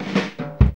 FILL 1   122.wav